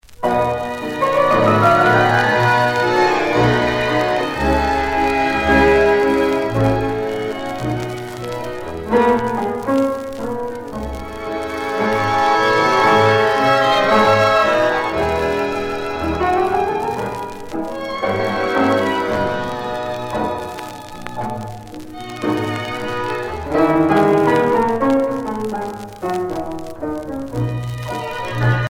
danse : tango